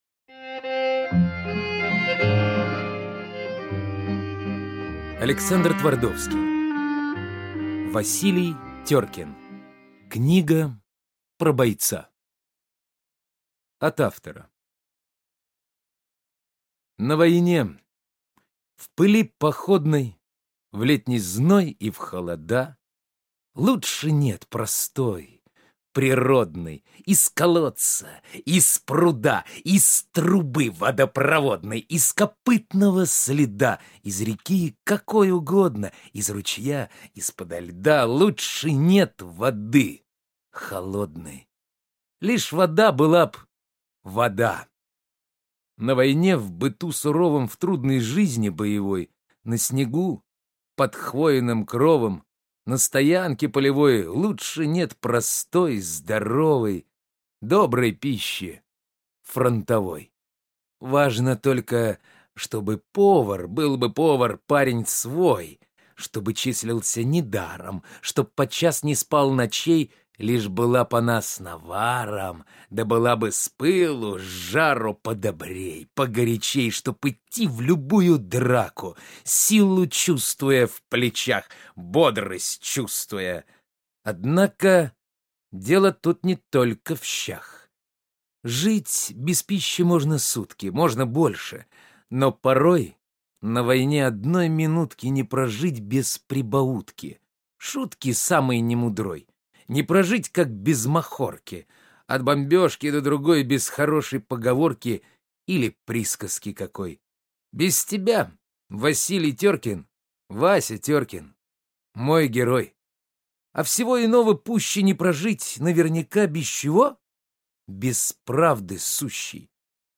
Аудиокнига Василий Теркин | Библиотека аудиокниг